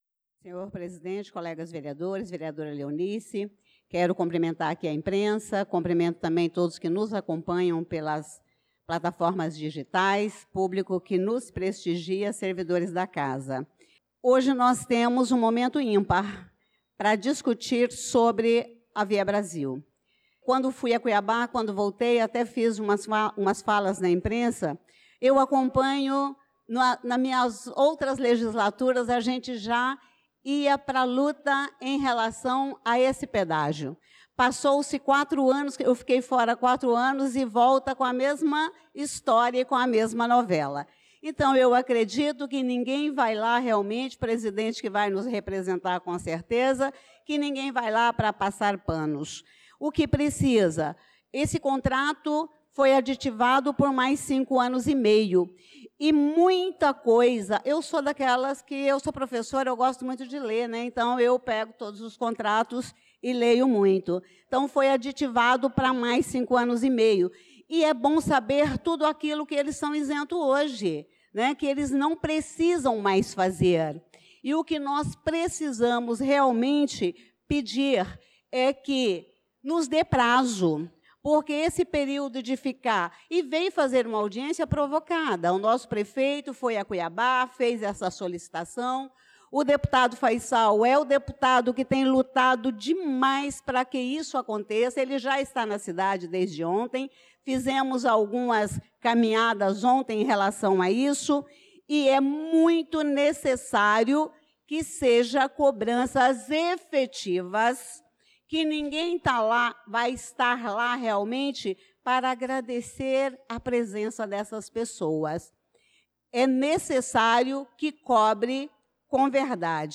Pronunciamento da vereadora Elisa Gomes na Sessão Ordinária do dia 18/03/2025